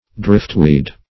Driftweed \Drift"weed`\, n. Seaweed drifted to the shore by the wind.